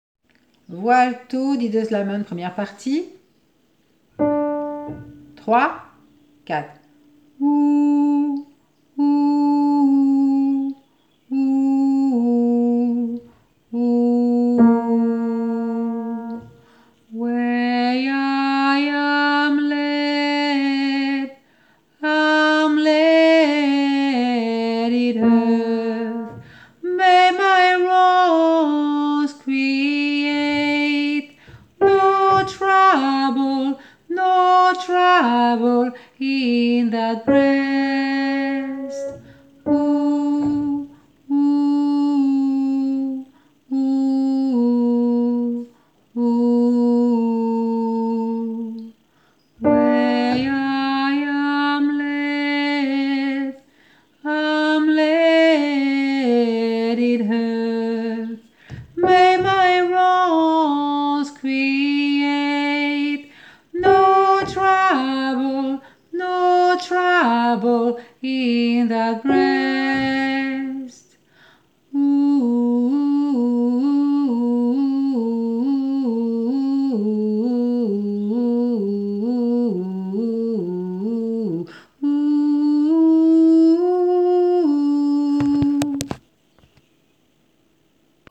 didos-lament-1ere-partie-voix-alto-1.mp3